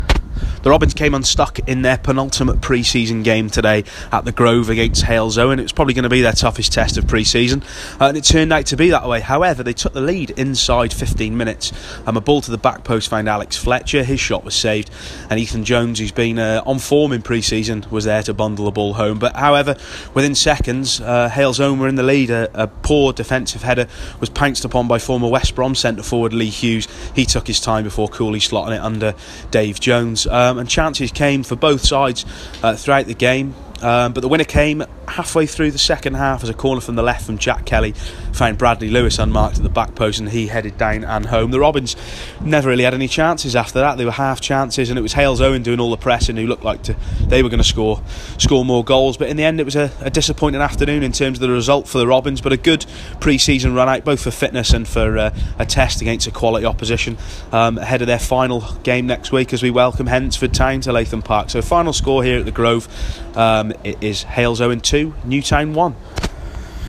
AUDIO REPORT l Halesowen Town 1-2 Robins